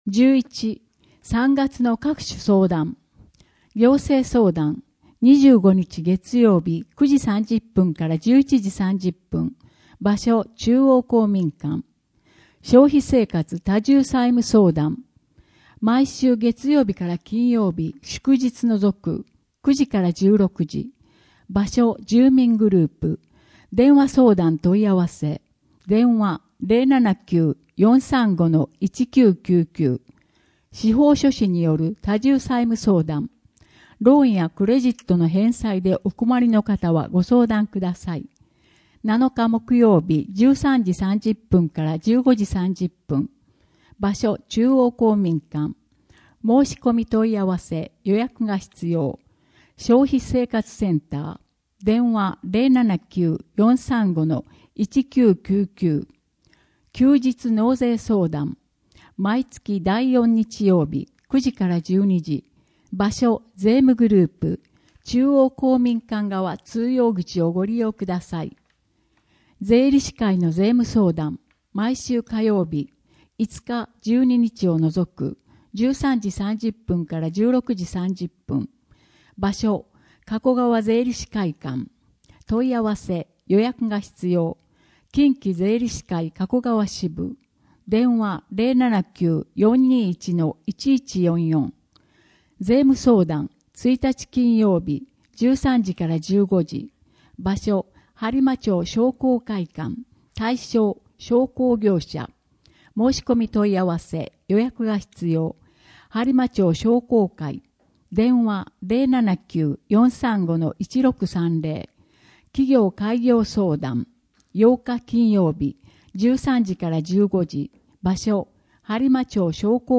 声の「広報はりま」3月号
声の「広報はりま」はボランティアグループ「のぎく」のご協力により作成されています。